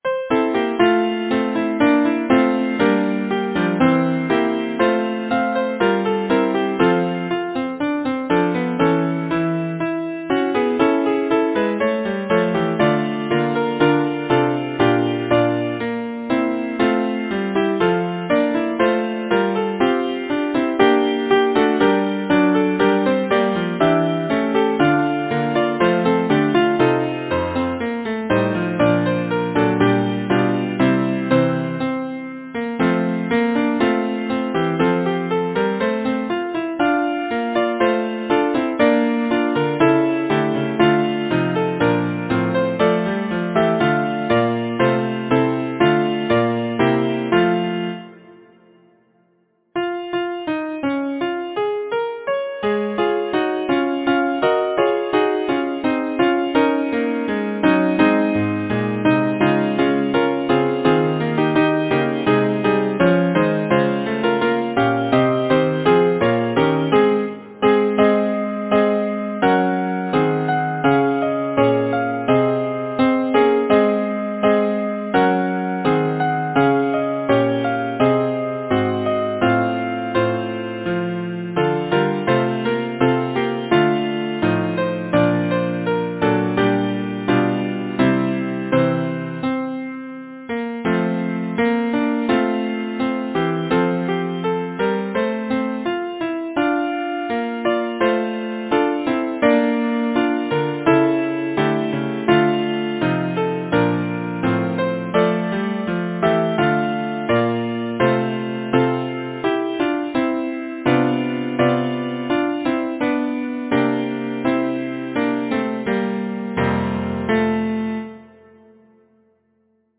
Title: Charming Chloe Composer: Ethel Mary Boyce Lyricist: Robert Burns Number of voices: 4vv Voicing: SATB Genre: Secular, Partsong
Language: English Instruments: A cappella